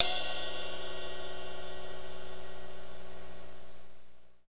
CYM P C RI0R.wav